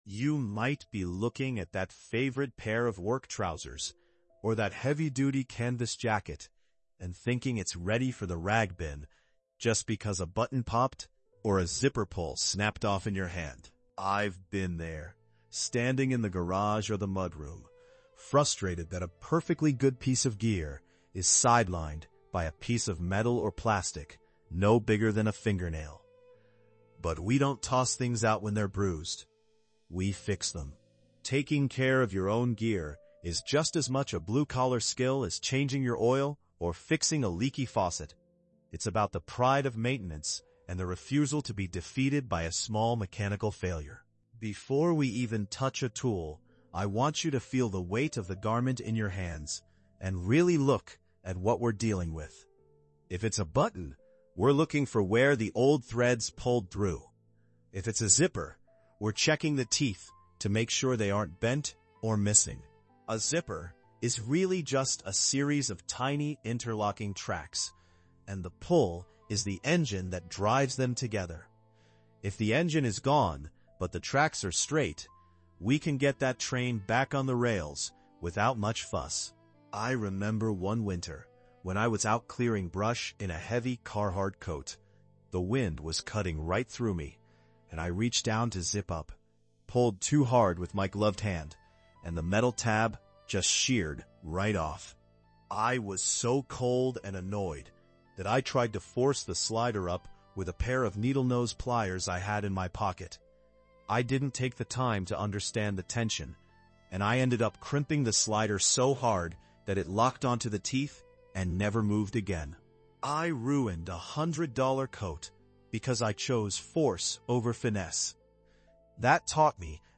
Through the lens of a calm, experienced mentor, we explore the sensory cues of a successful repair—from the specific resistance of a needle through heavy canvas to the rhythmic hum of a well-lubricated zipper.